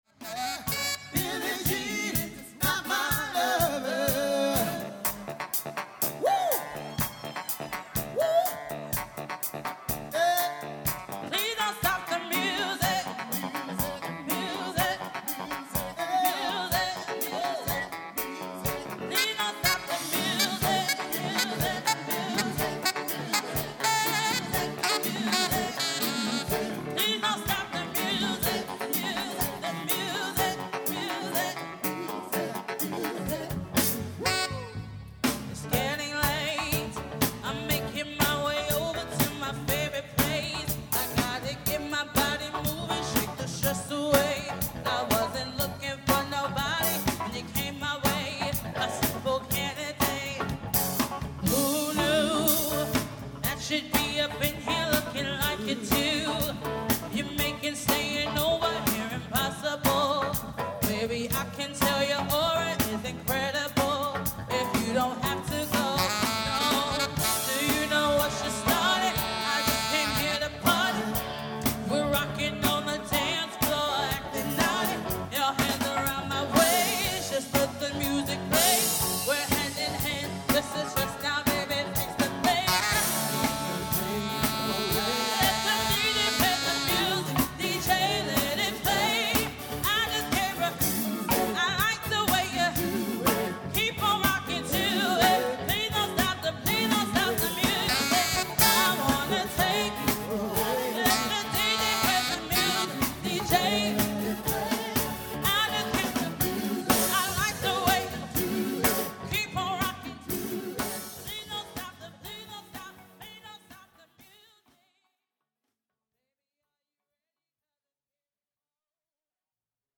Popular, Funk, Dance